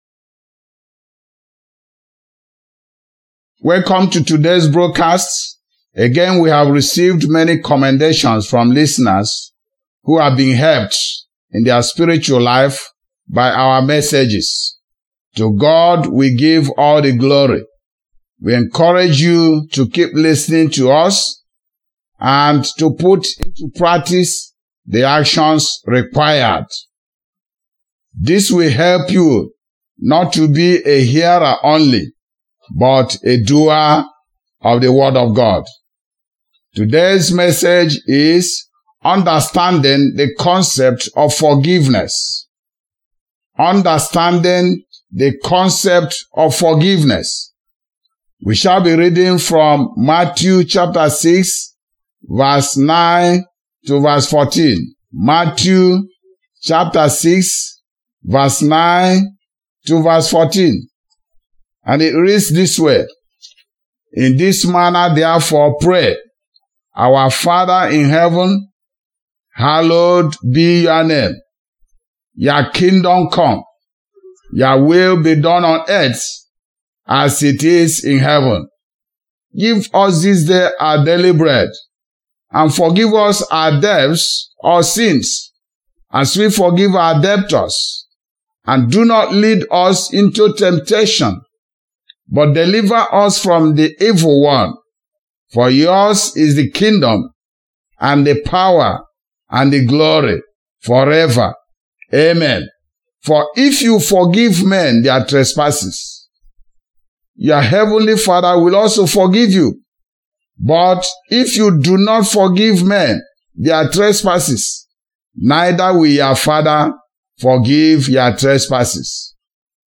Welcome to today’s broadcast.